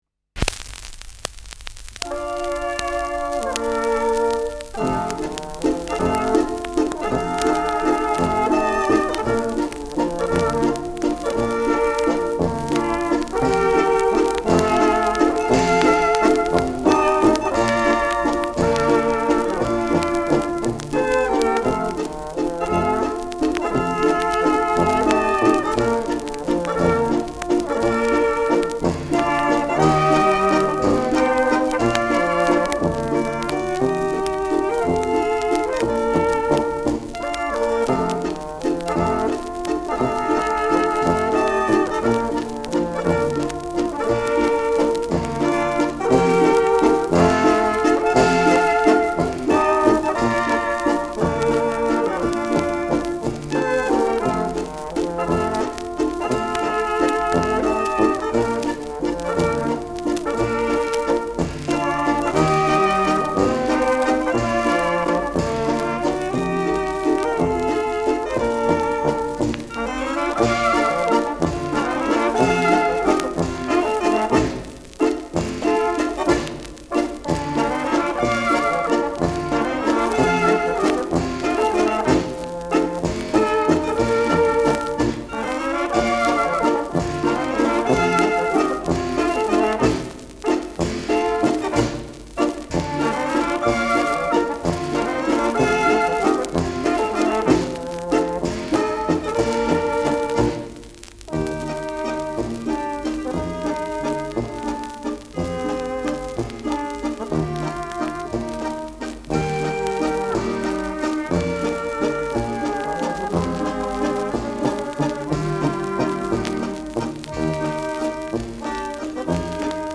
Walzer
Schellackplatte
Abgespielt : Durchgehend leichtes bis stärkeres Knacken
Folkloristisches Ensemble* FVS-00015